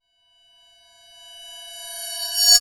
time_warp_reverse_high_01.wav